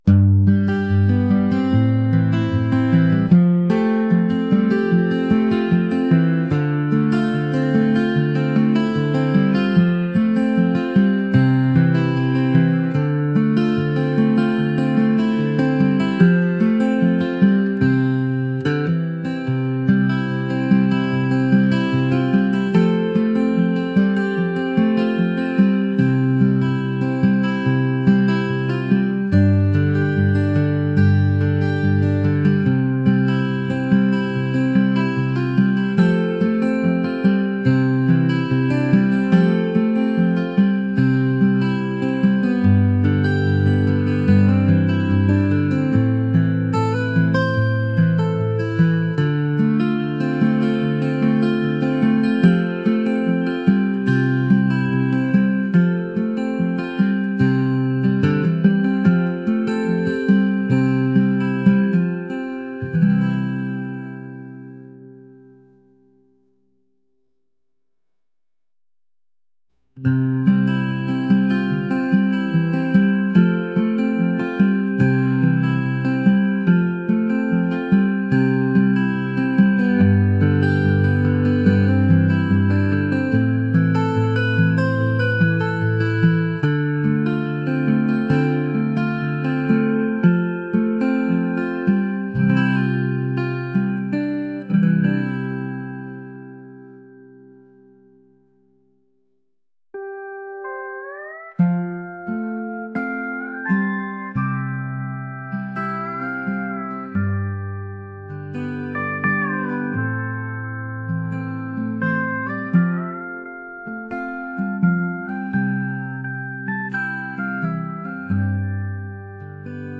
mellow | folk